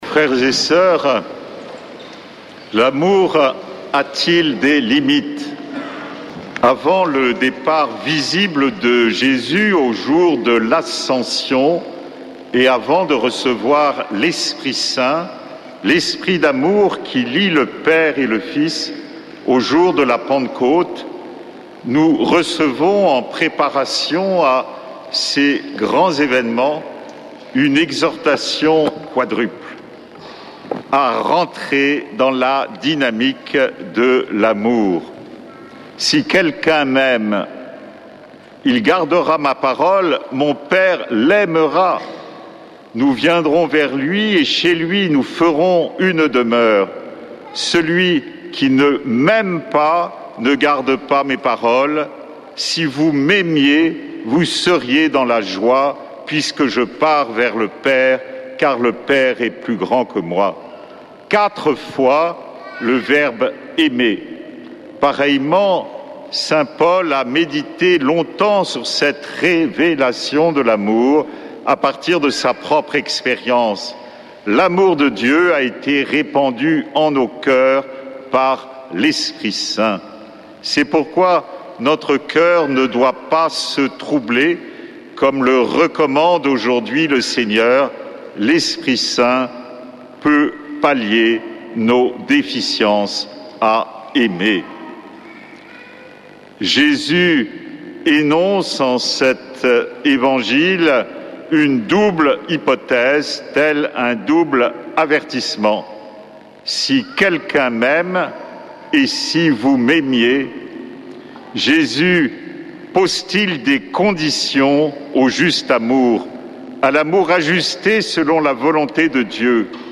dimanche 25 mai 2025 Messe depuis le couvent des Dominicains de Toulouse Durée 01 h 30 min